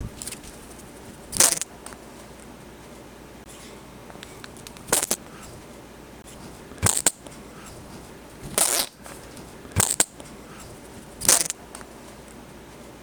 attack verbalization while doing this.